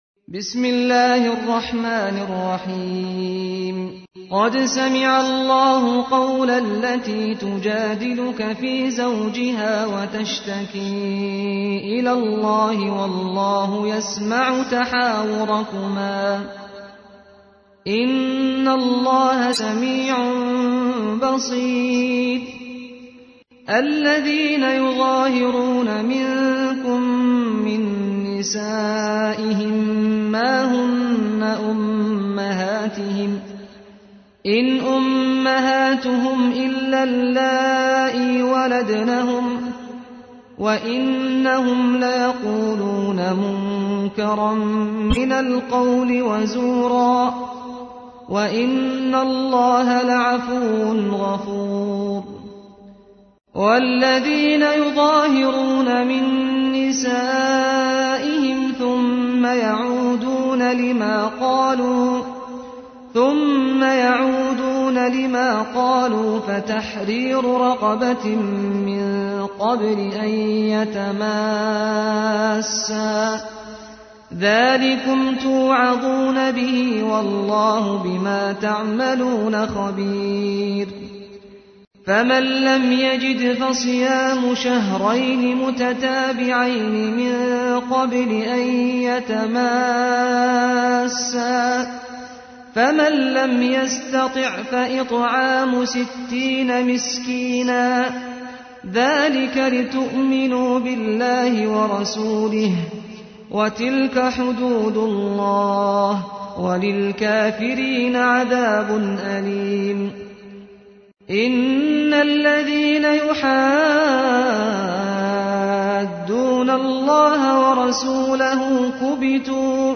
ترتیل سوره مجادله با صدای استاد سعد الغامدی